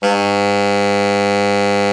TENOR 1.wav